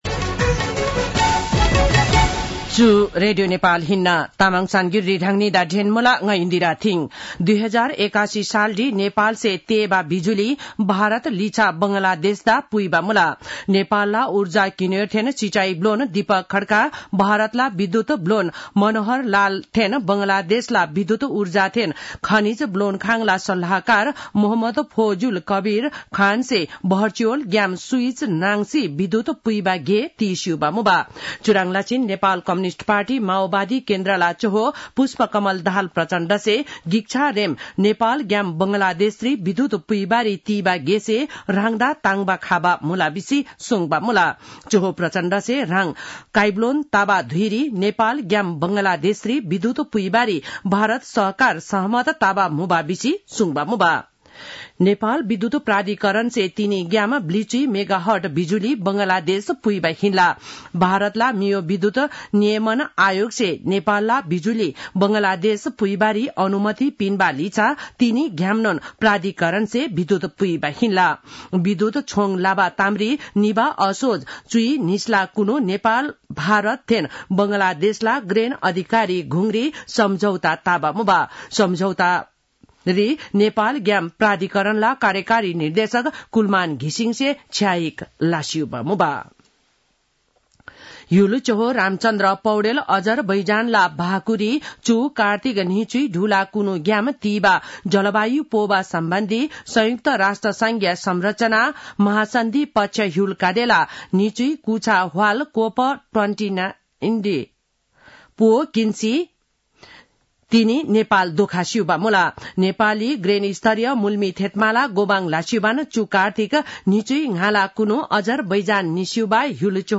तामाङ भाषाको समाचार : १ मंसिर , २०८१
Tamang-news-7-30.mp3